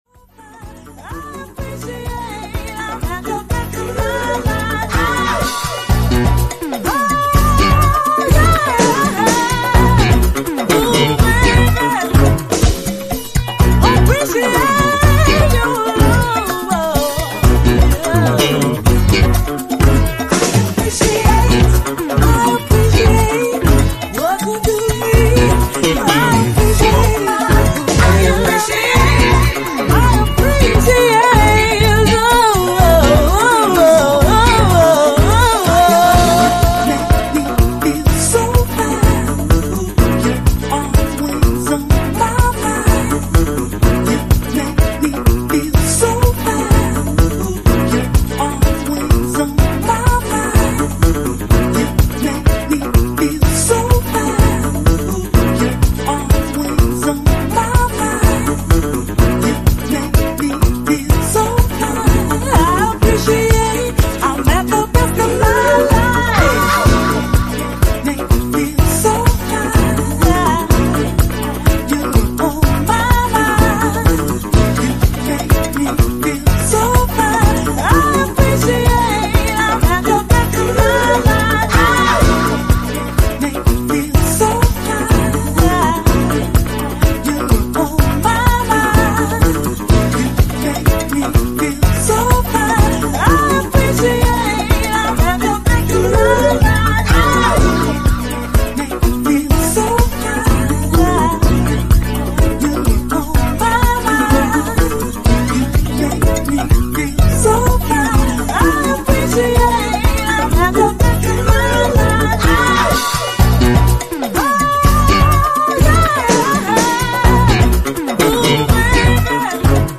ジャンル(スタイル) DISCO HOUSE / FUNKY HOUSE